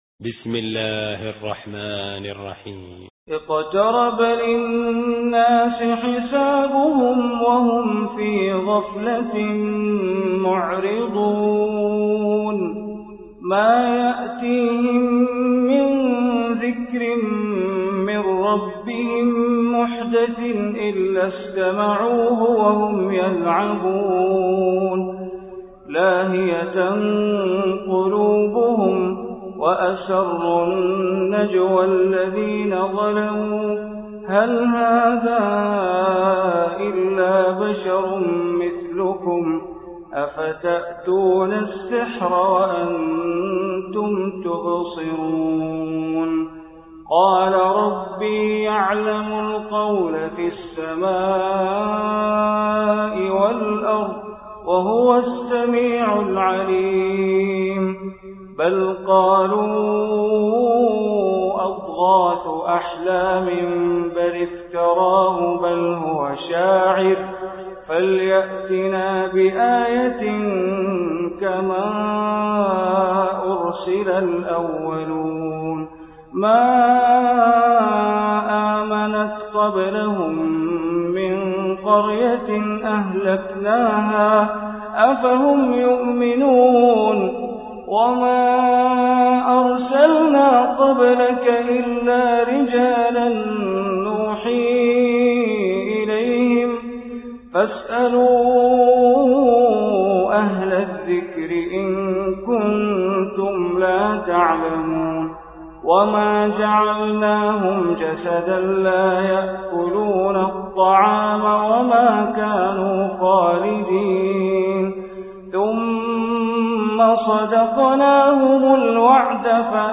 Surah Anbiya Recitation by Sheikh Bandar Baleela
Surah Anbiya, listen online mp3 tilawat / recitation in Arabic, recited by Imam e Kaaba Sheikh Bandar Baleela.